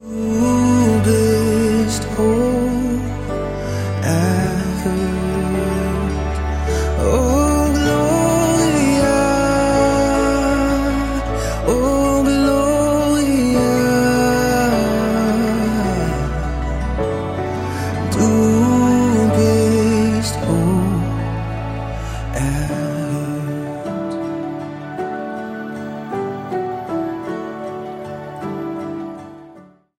ihr erstes, lang erwartetes Studio-Album